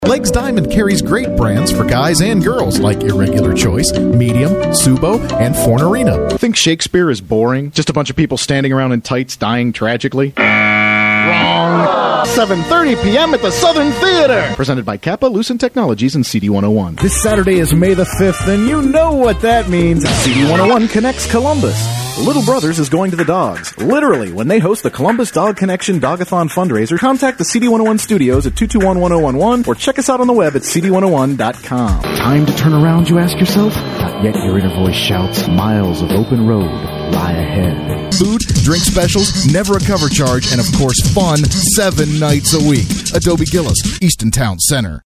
Demo Reel
Voice - commercial (45 sec)